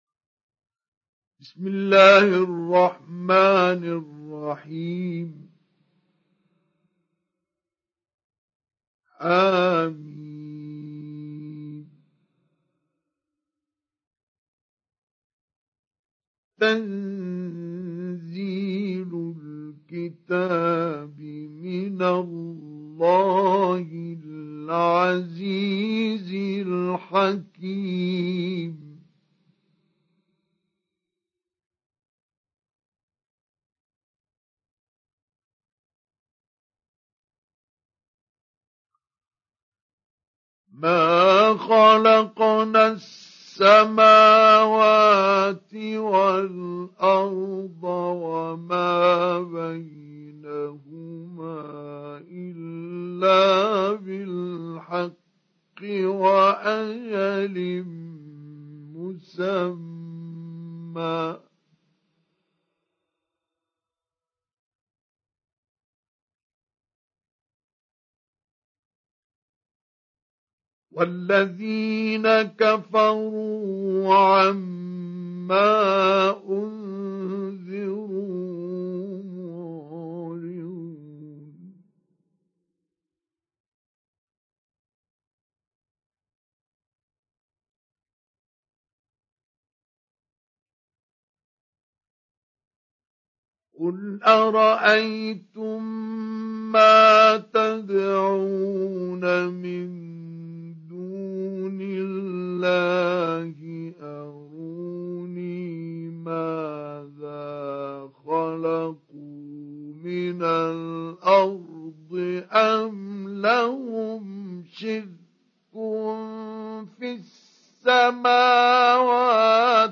سُورَةُ الأَحۡقَافِ بصوت الشيخ مصطفى اسماعيل